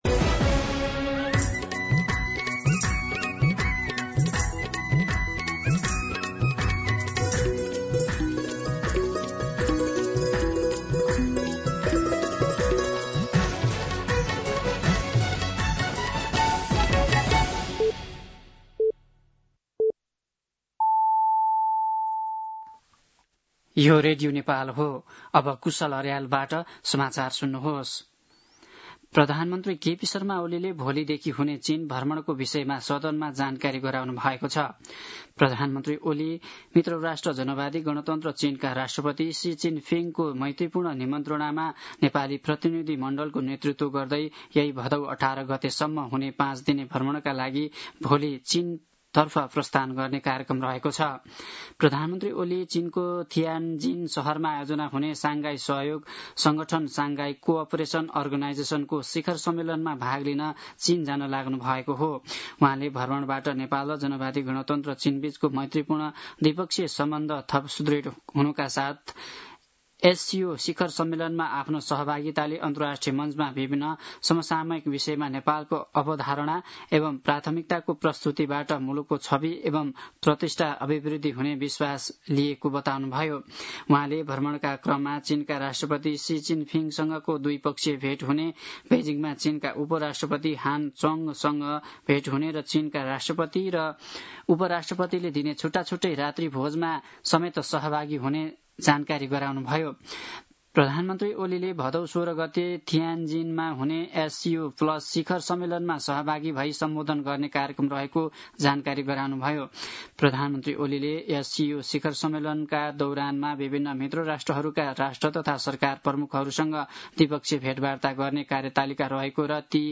दिउँसो ४ बजेको नेपाली समाचार : १३ भदौ , २०८२
4pm-News-05-13.mp3